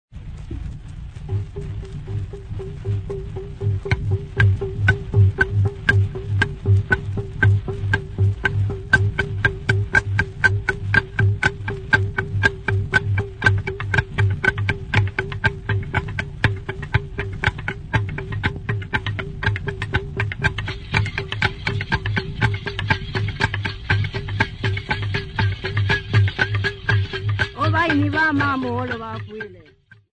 group of boys at Mapanza
Folk Music
Field recordings
sound recording-musical
Indigenous music